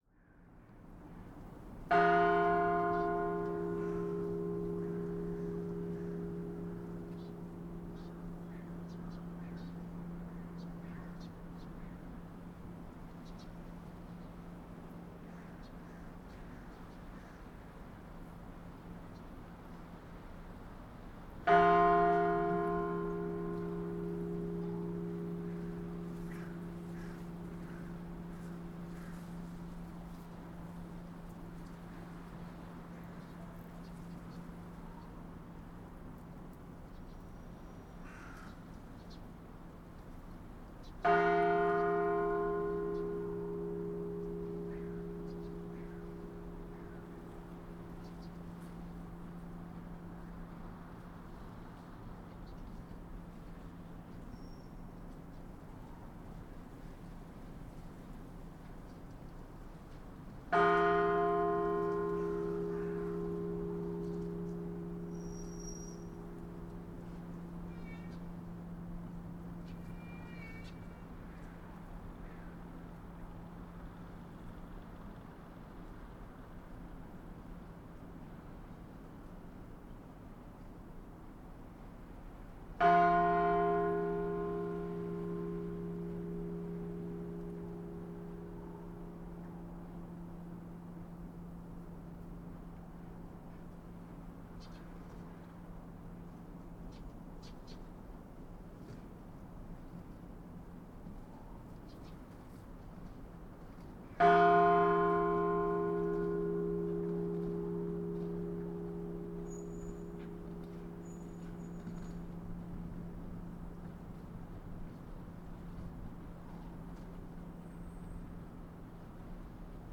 BELLLrg_Bell Chimes.Bell Ringing.Orthodox Church 1_EM
bell bells bell-tower big chapel chime church church-bell sound effect free sound royalty free Sound Effects